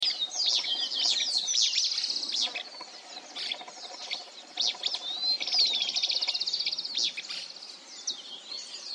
Uí-pi (Synallaxis albescens)
Nome em Inglês: Pale-breasted Spinetail
Fase da vida: Adulto
Localidade ou área protegida: General Pico
Condição: Selvagem
Certeza: Fotografado, Gravado Vocal
Pijui-cola-parda.mp3